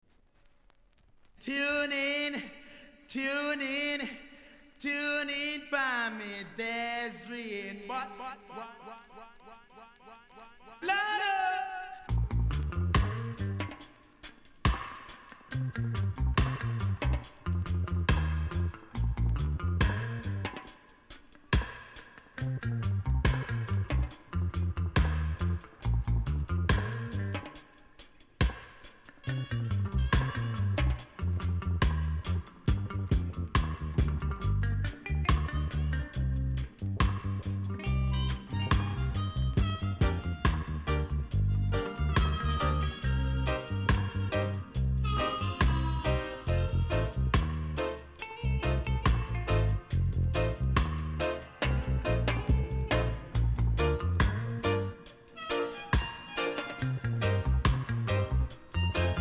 BIG DISCO 45!!